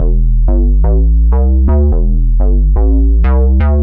cch_bass_lakeside_125_Dm.wav